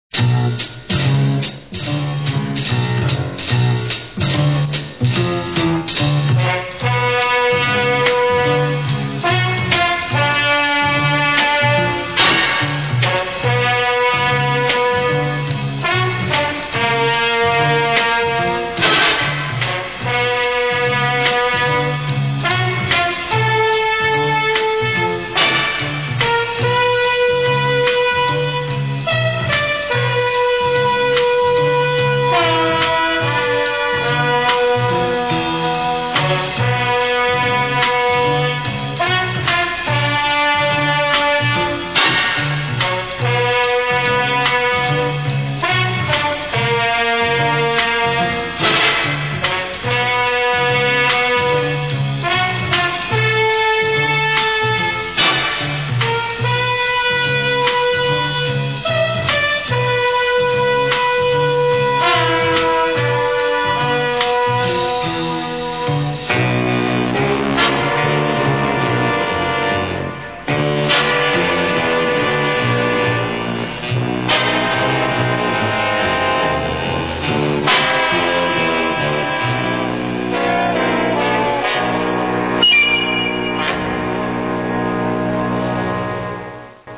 Générique :